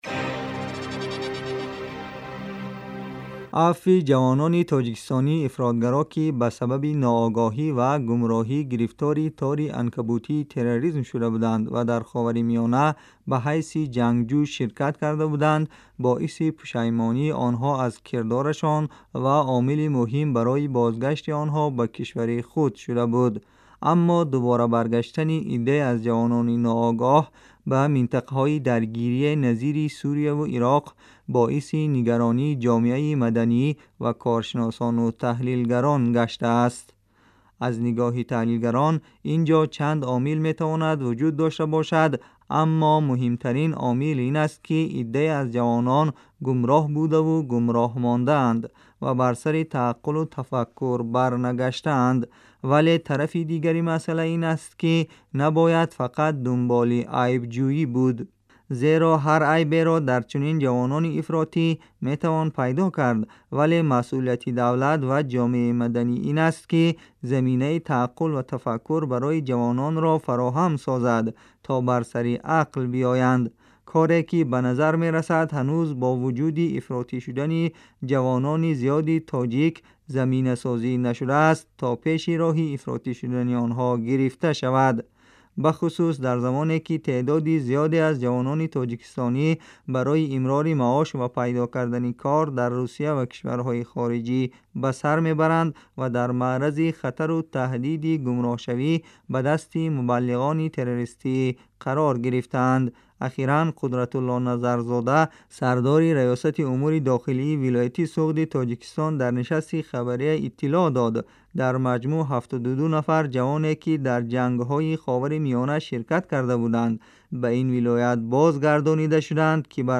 гузориши вижа